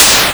Touhou-SFX - A collection of Touhou-like and 2hu relevant audio that I've collected as I went about dev-ing games.
bullet_sharp_bright.wav